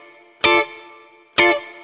DD_StratChop_130-Dmin.wav